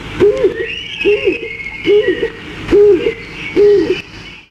Pigeon colombin
Columba oenas
pigeon_c.mp3